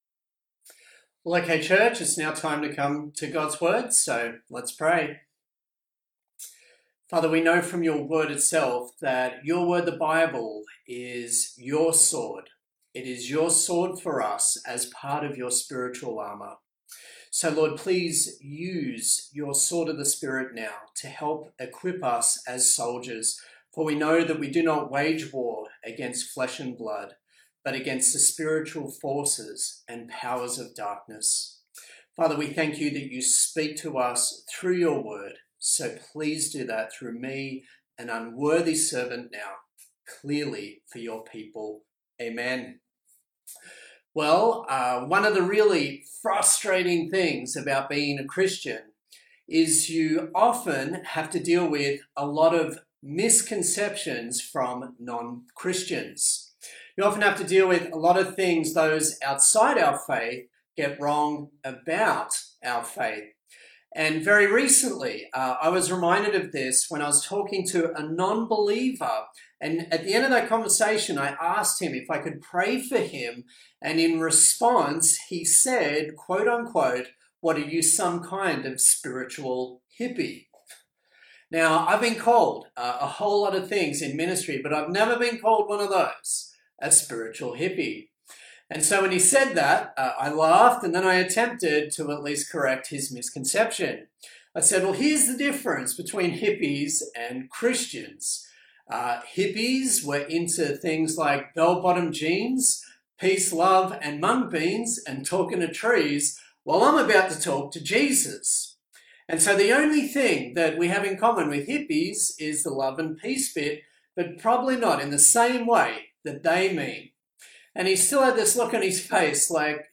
Acts Passage: Acts 17:1-15 Service Type: Sunday Morning